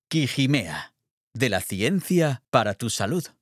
Medical Narration
Neumann U-87, Pro Tools, Audition, Nuendo, Audient & UAudio interfaces.
ConversationalWarmBrightConfidentCharismaticUpbeat